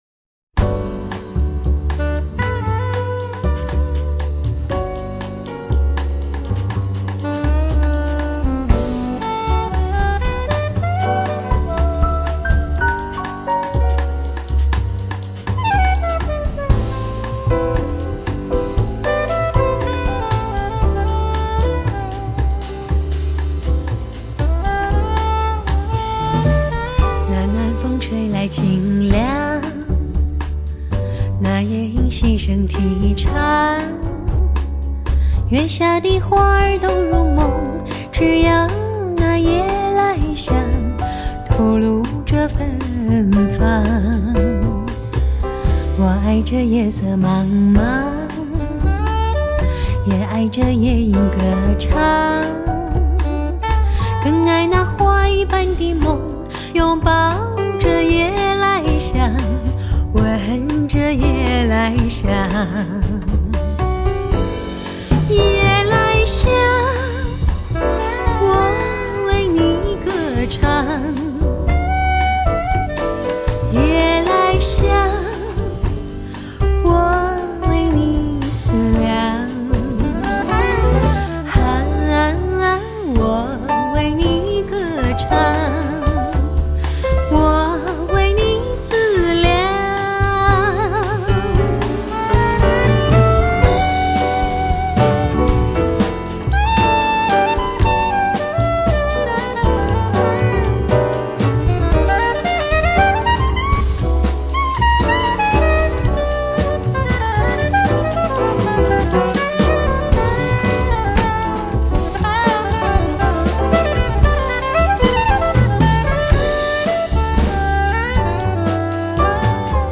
[华语发烧]
这张经过24比特HDCD技术处理的唱片，肯定是今年上市的国产录音中优秀的女声天碟之一。